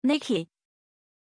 Aussprache von Nici
pronunciation-nici-zh.mp3